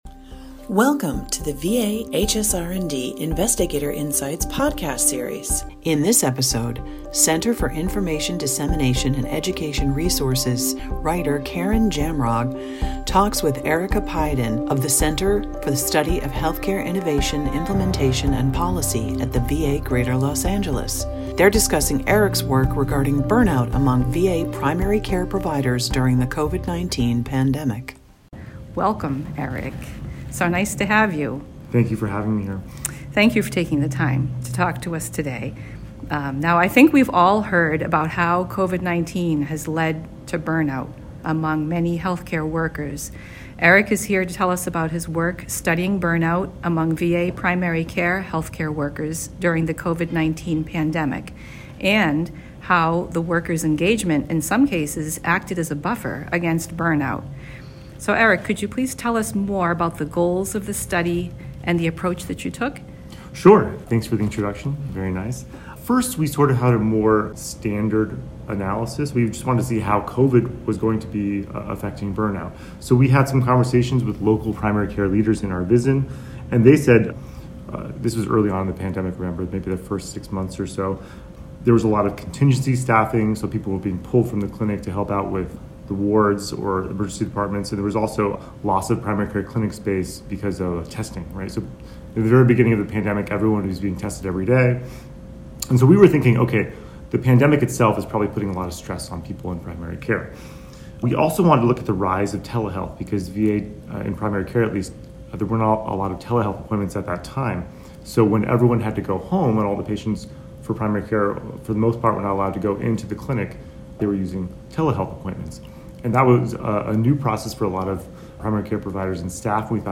at the 2023 HSR&D national meeting